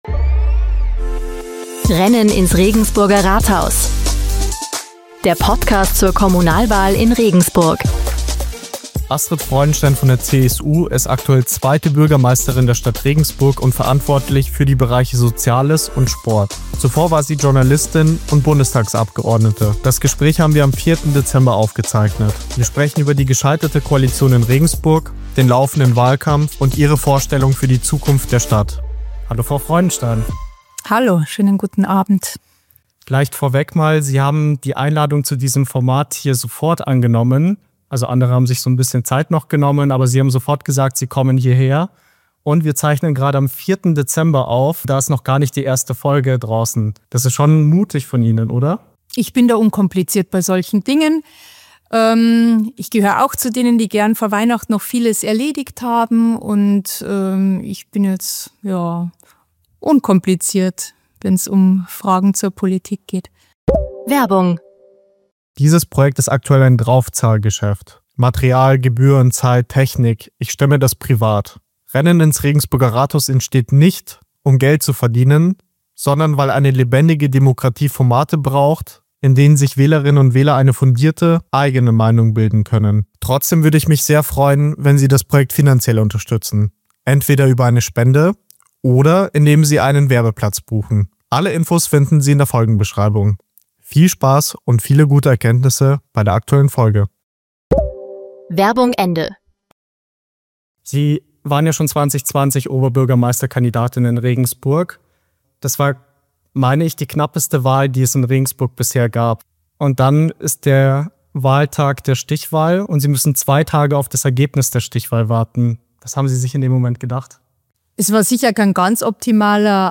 In dieser Folge spreche ich mit Astrid Freudenstein, zweiter Bürgermeisterin und Oberbürgermeisterkandidatin der CSU.